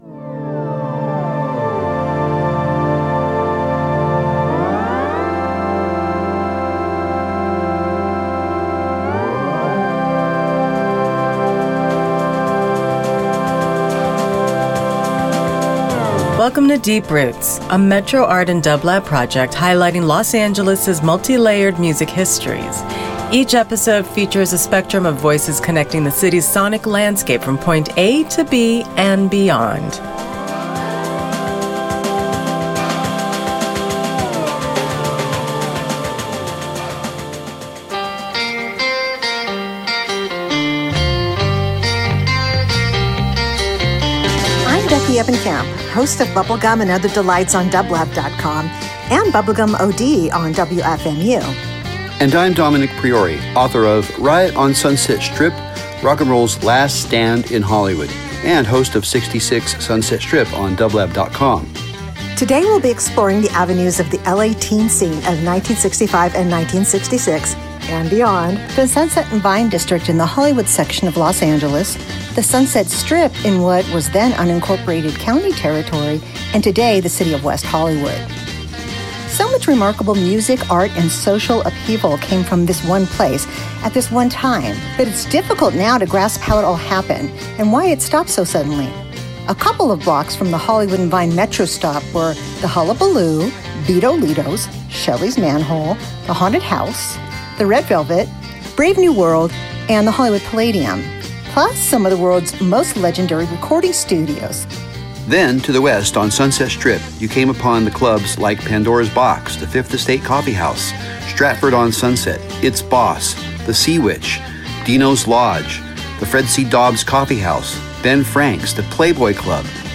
Each episode is a multimedia dive into the various intersecting musical histories embedded into the streets, buildings, and neighborhoods of Los Angeles. Hear from a spectrum of musicians who defined their respective scenes along with a collaged soundtrack of hand selected music, interviews, archival photos, and a broadcasted livestream of the accompanying geographies as seen from a Metro Bike as visual accompaniment.